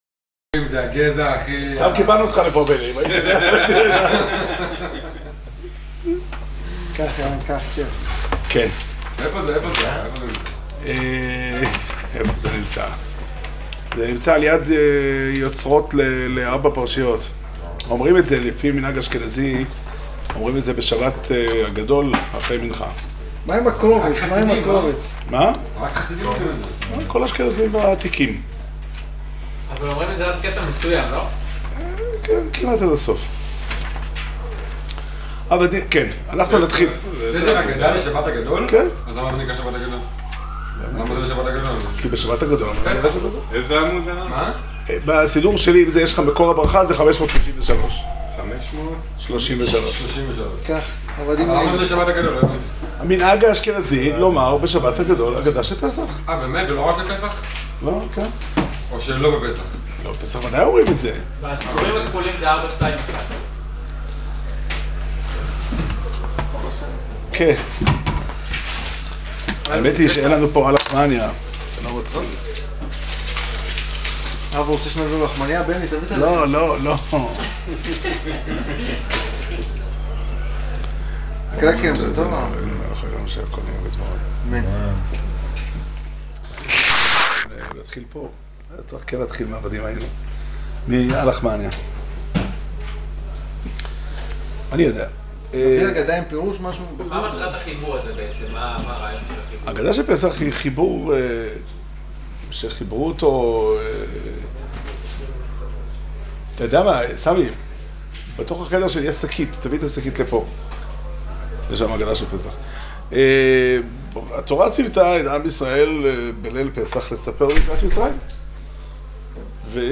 שיעור שנמסר בבית המדרש 'פתחי עולם' בתאריך י"ח אדר ב' תשע"ט